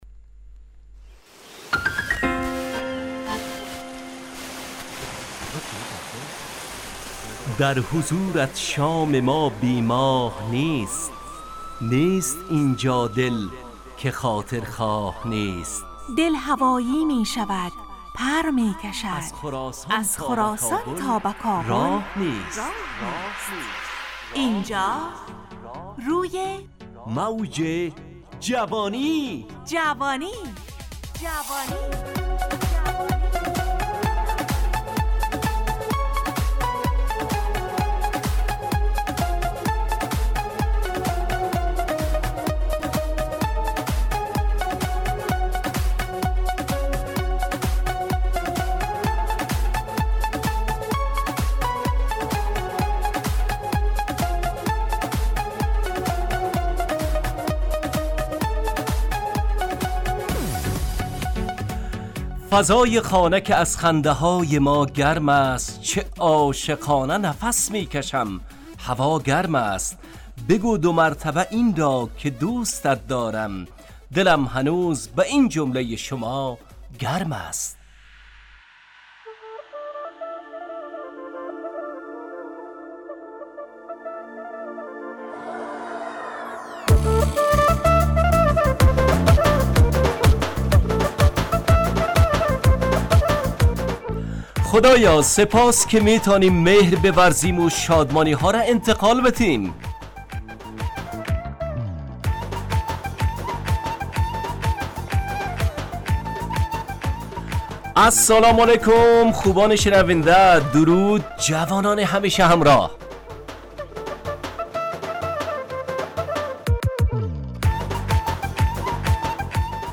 همراه با ترانه و موسیقی مدت برنامه 55 دقیقه . بحث محوری این هفته (عیادت) تهیه کننده